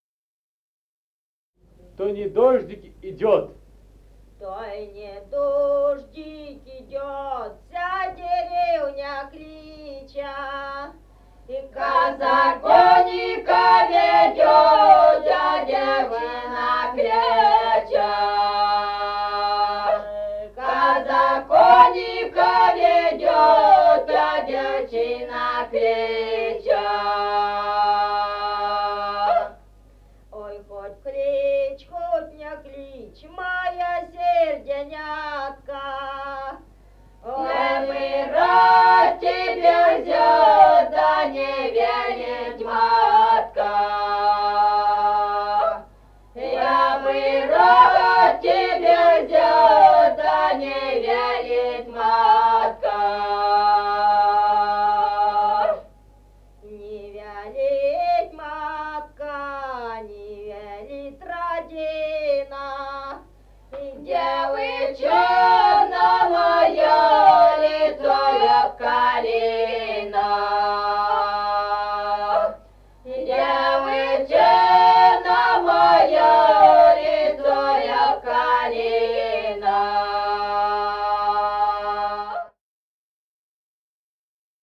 Музыкальный фольклор села Мишковка «То й не дождик идёт», лирическая.